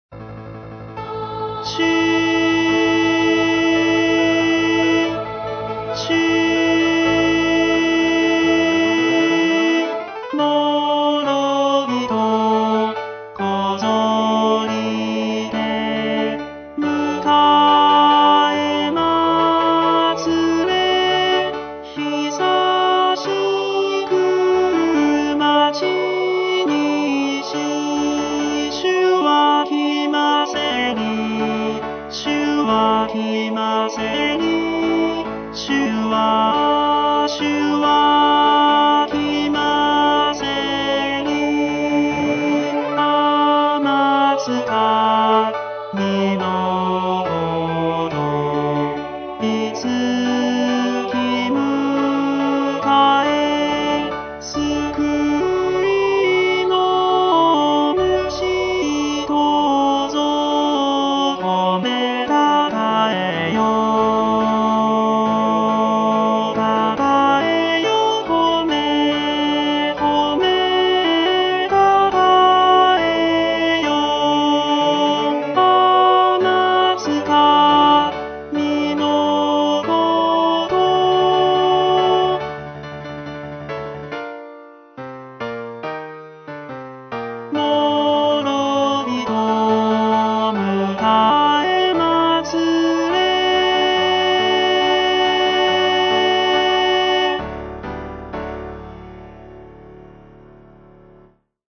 浦和ｗ用　4パート編曲版　テンポ遅い　練習用
テノール（歌詞付き）